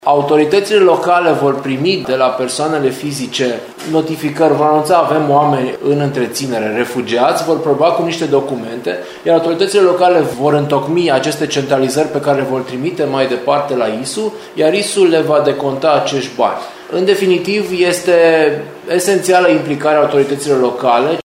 Prefectul de Timiș, Mihai Ritivoiu, a explicat cum pot fi decontați banii.